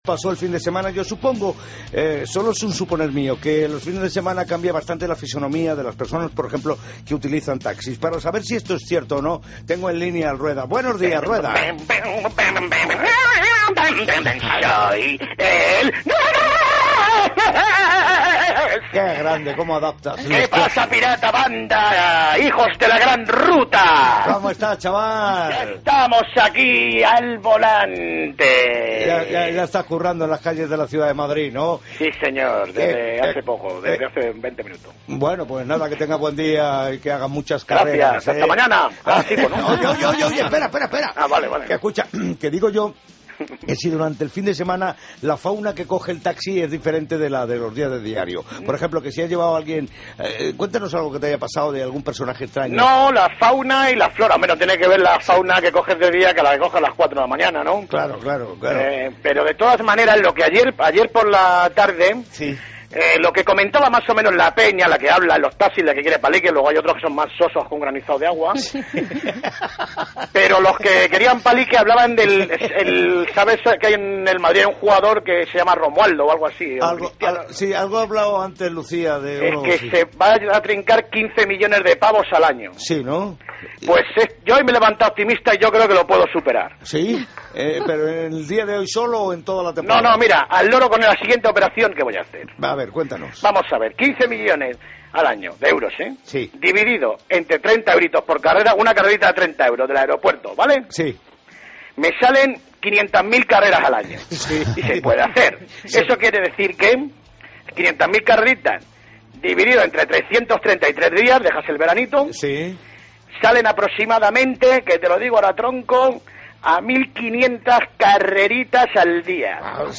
AUDIO: El Piratas llama al Ruedas y le explica las cuentas de CR7 y lo que hay que trabajar para igualarlas